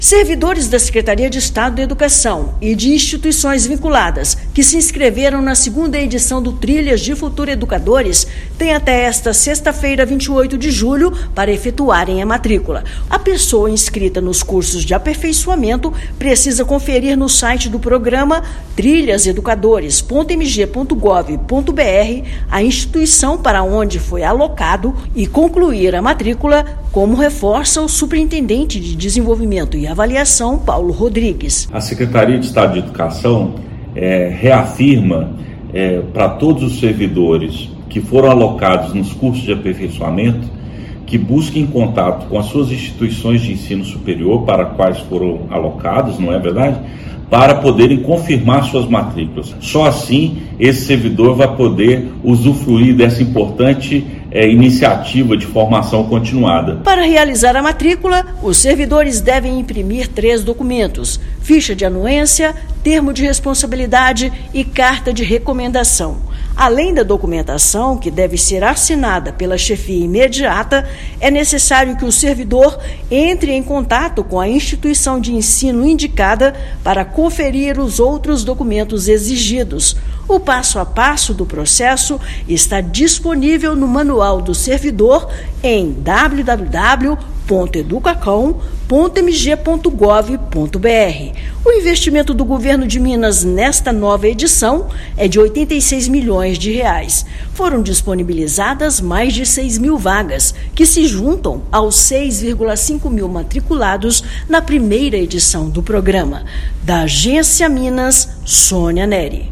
Secretaria de Educação alerta que os inscritos contemplados pelo programa precisam confirmar a matrícula na instituição indicada. Ouça matéria de rádio.